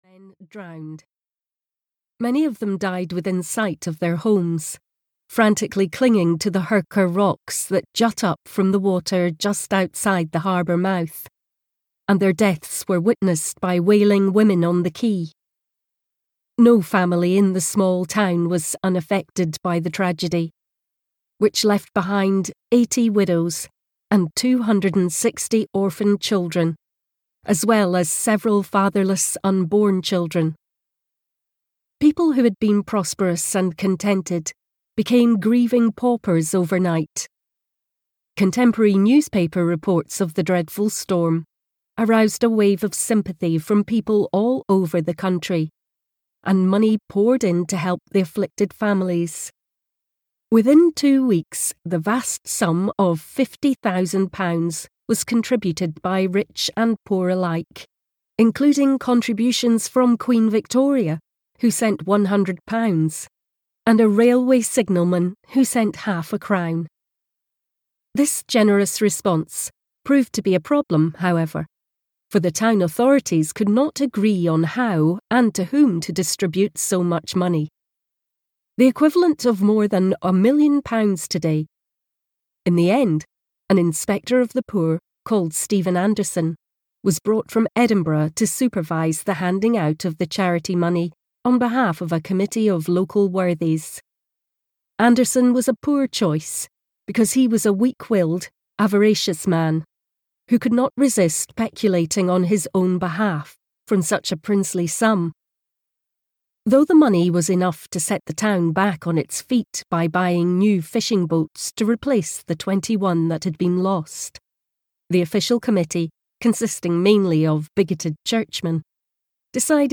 Turn of the Tide (EN) audiokniha
Ukázka z knihy